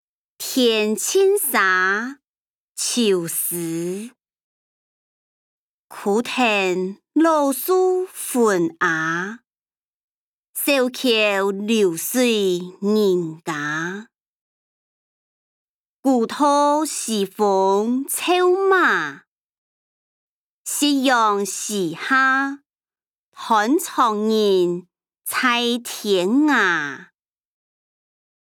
詞、曲-天淨沙：秋思音檔(四縣腔)